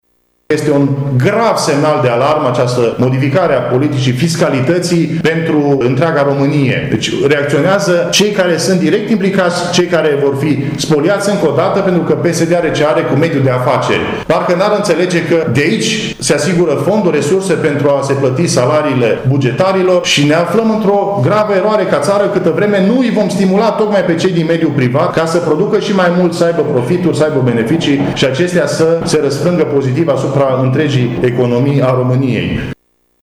Marius Pașcan a criticat astăzi, într-o conferință de presă, componența noului executiv.